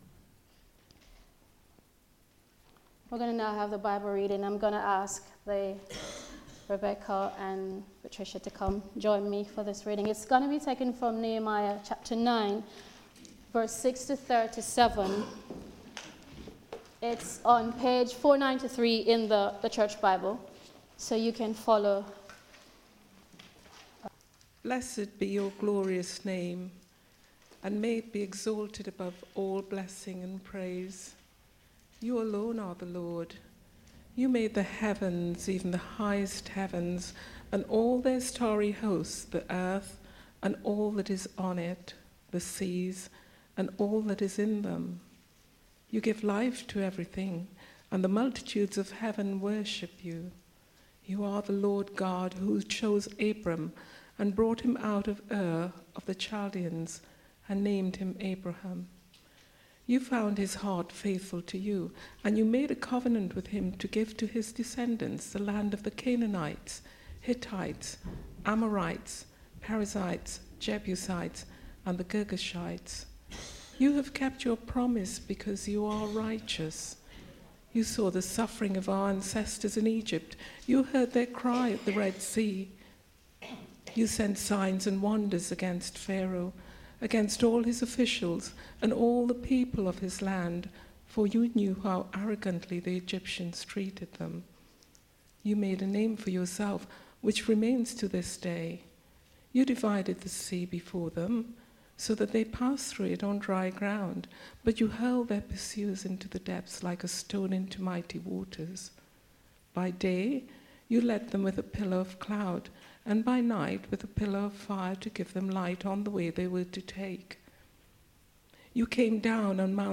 Prayer Series Theme: Corporate Prayer Sermon